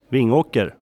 pronunciation) is a locality and the seat of Vingåker Municipality, Södermanland County, Sweden with 4,282 inhabitants in 2010.[1] It is located 50 kilometres (31 mi) by road from the nearest larger city Örebro and 80 kilometres (50 mi) north-west of county seat Nyköping.